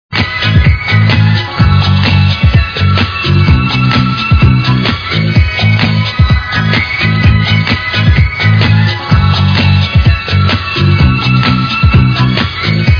tous les titres house du moment mixés !!!